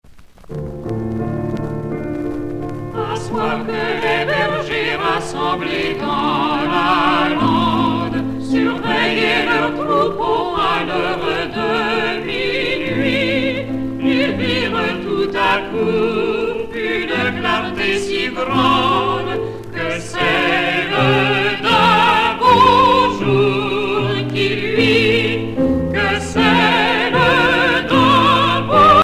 Noël, Nativité
Genre strophique
Pièce musicale éditée